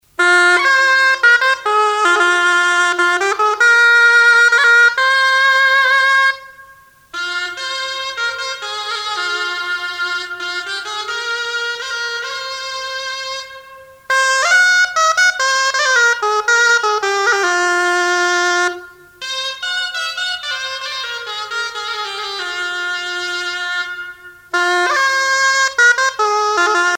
les hautbois
Pièce musicale éditée